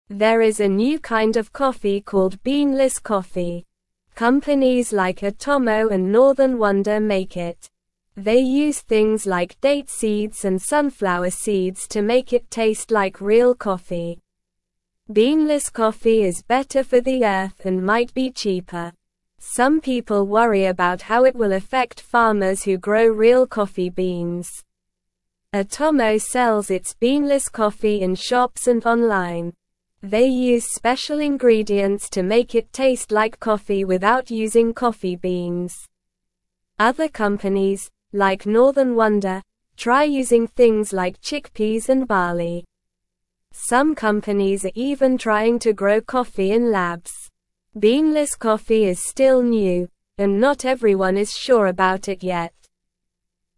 Slow
English-Newsroom-Beginner-SLOW-Reading-Beanless-Coffee-A-New-Kind-of-Earth-Friendly-Brew.mp3